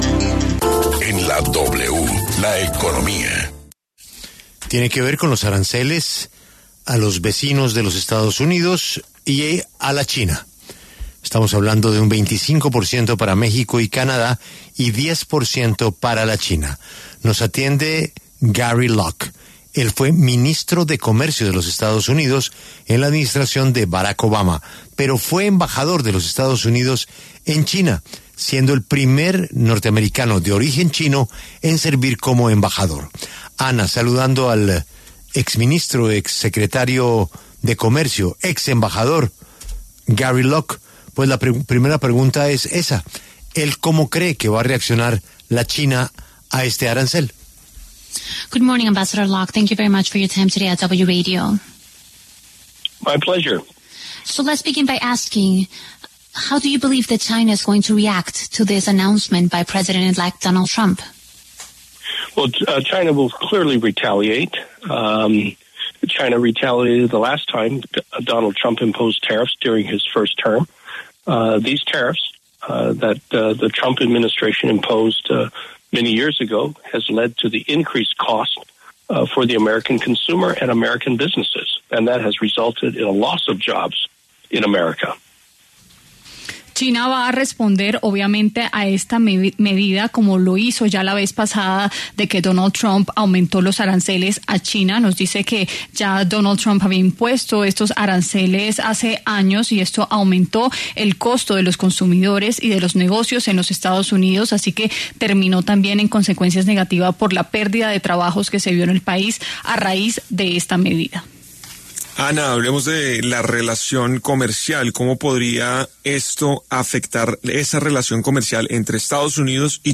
Análisis: ¿cómo reaccionará China ante los nuevos aranceles que impondrá Donald Trump?
Gary Locke, exsecretario de Comercio de Estados Unidos, se refirió en La W al anuncio del presidente electo Donald Trump de aumentar la apuesta por los aranceles.